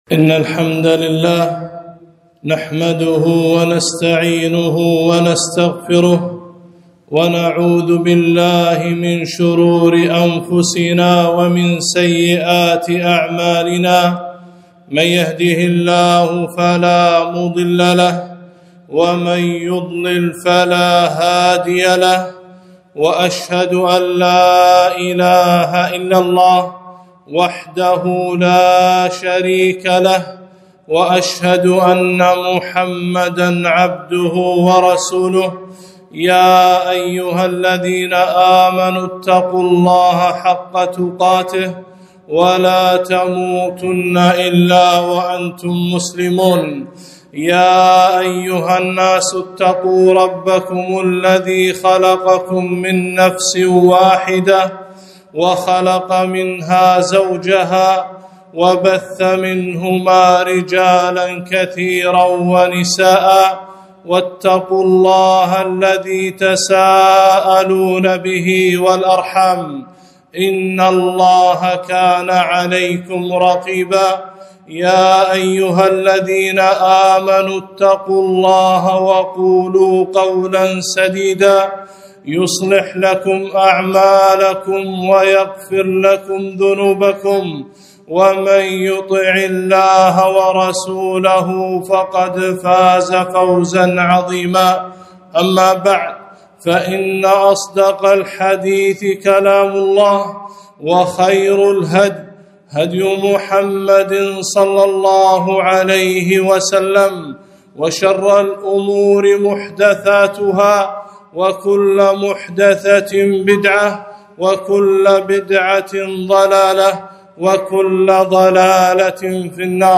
خطبة - يوم الجمعة آداب وأحكام